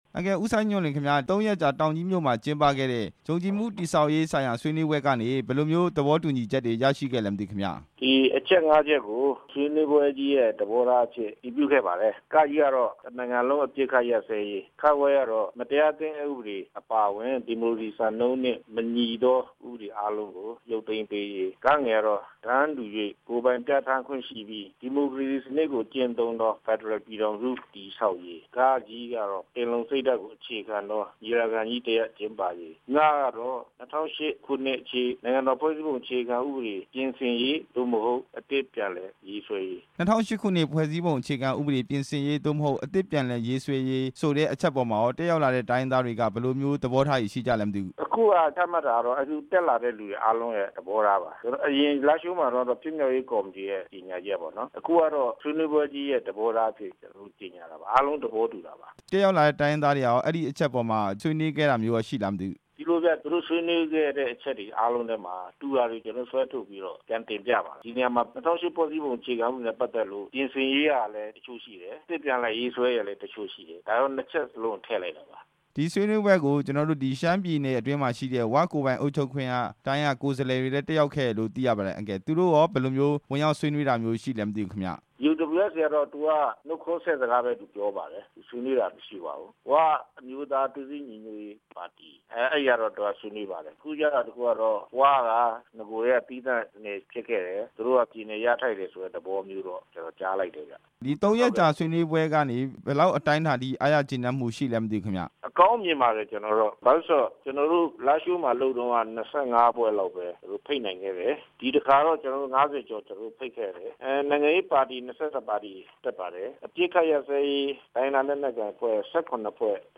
ဆွေးနွေးပွဲအကြောင်း တင်ပြချက်